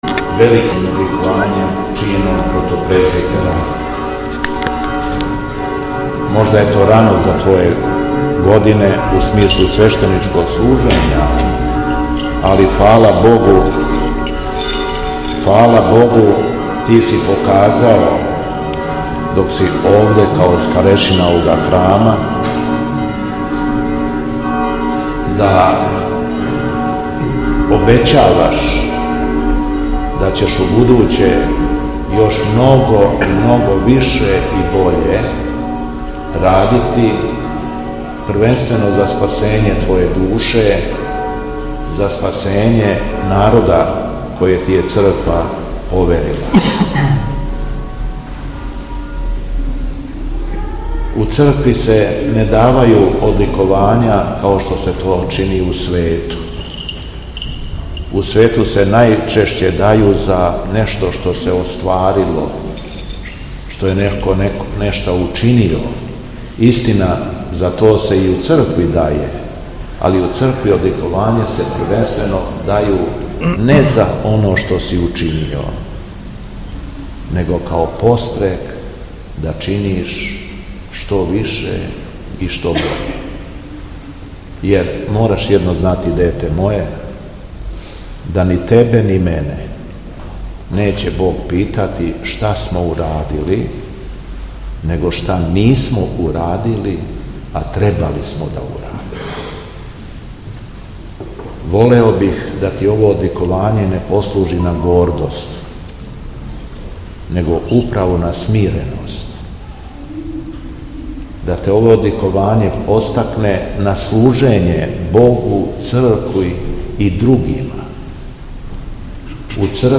Духовна поука Његовог Високопреосвештенства Митрополита шумадијског г. Јована